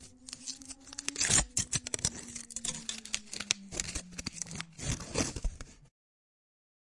锡箔纸 " 锡箔纸04
描述：处理，起皱，嘎吱嘎吱和撕裂铝/锡箔片。 用Tascam DR40录制。
标签： 沙沙声 拟音 撕裂 起皱 挤压 金属 锡 - 箔 金属 撕裂 紧缩 金属箔 处理 处理 铝箔 锡箔
声道立体声